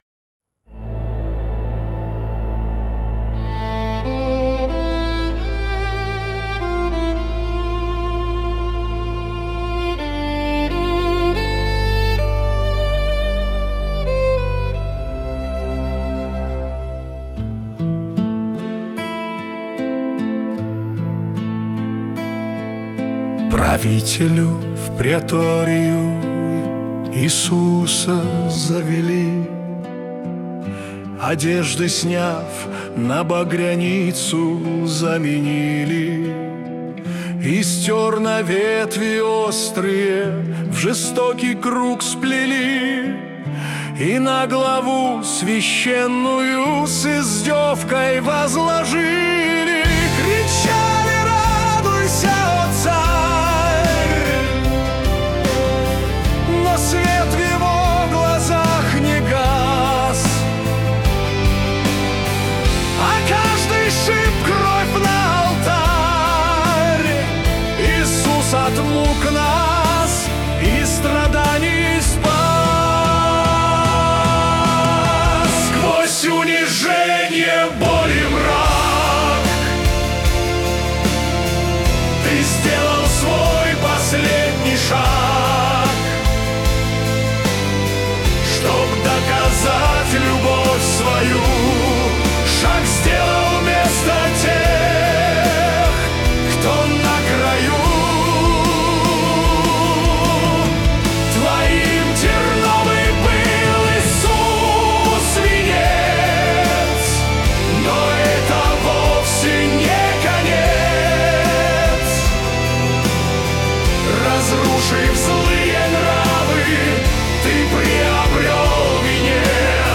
188 просмотров 691 прослушиваний 62 скачивания BPM: 76